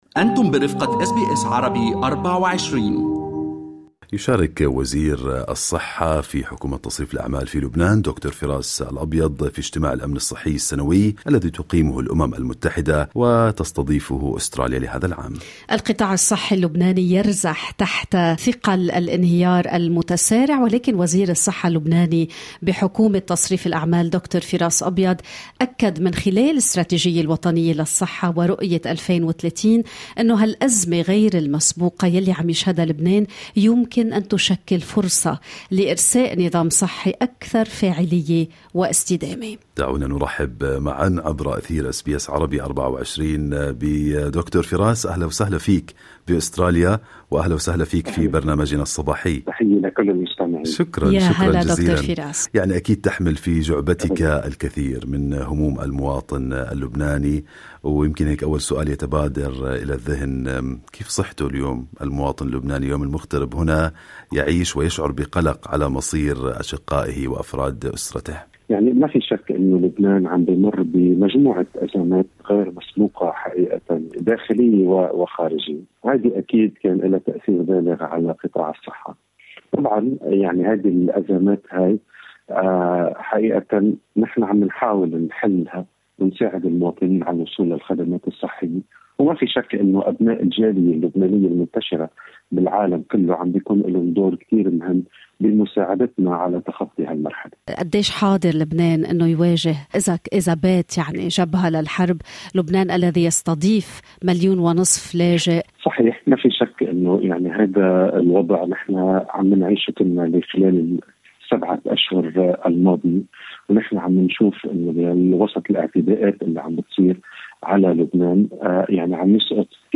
"أدوية تكفي لأربعة أشهر": وزير الصحة اللبناني يتحدث عن جهوزية البلاد مع اقتراب شبح الحرب